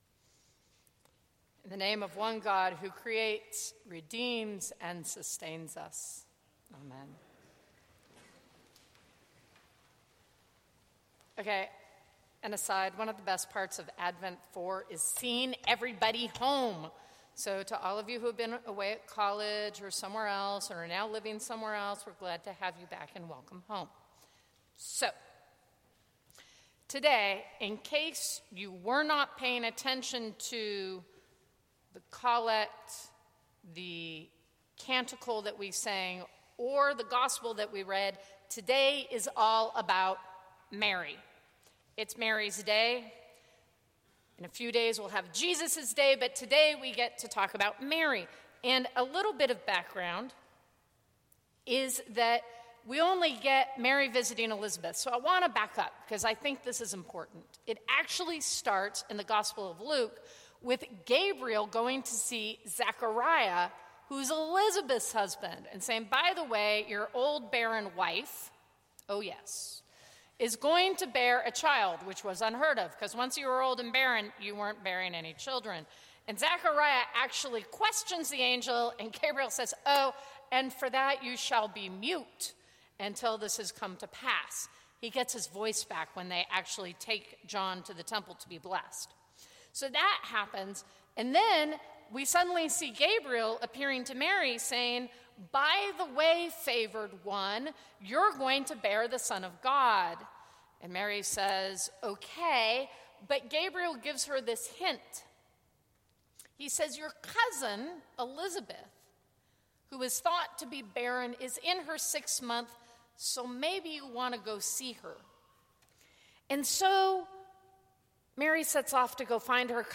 Sermons from St. Cross Episcopal Church Embrace Mercy Dec 21 2015 | 00:16:44 1x 00:00 / 00:16:44 Subscribe Share Apple Podcasts Spotify Overcast RSS Feed Share Link Embed